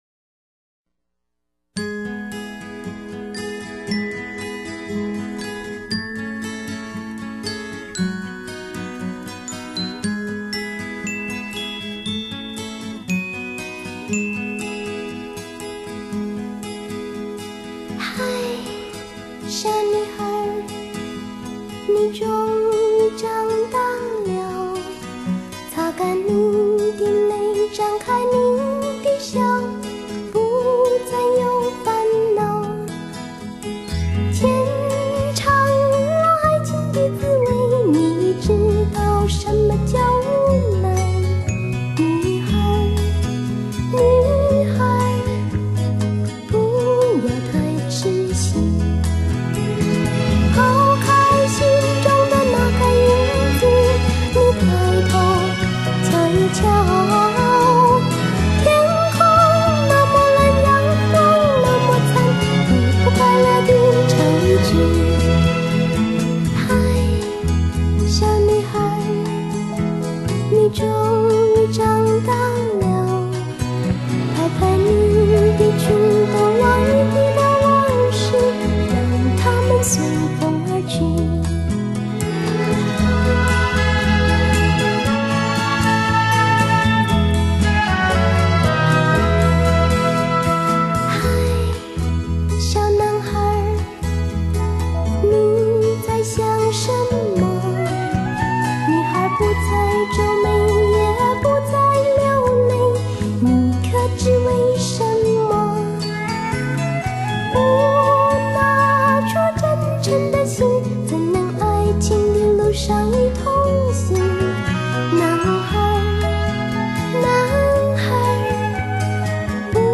正宗絕對原聲原唱!